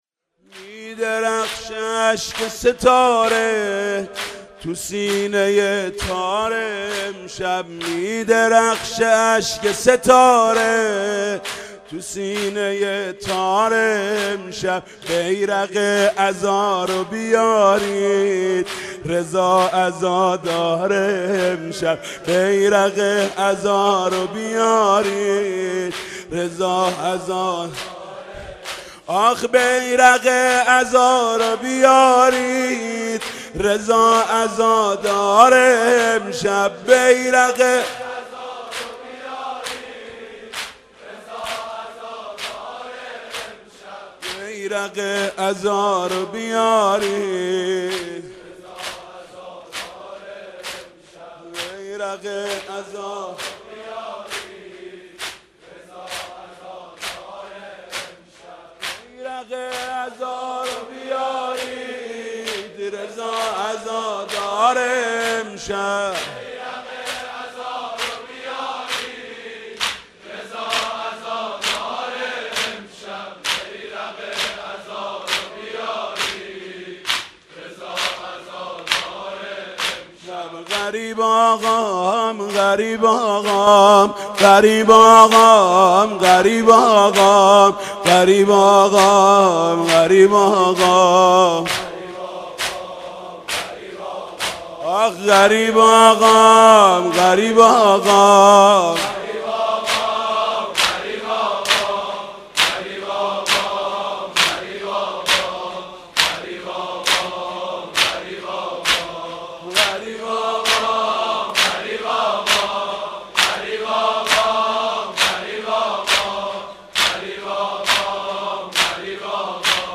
بیانات مقام معظم رهبری پیرامون زندگی امام جواد علیه السلام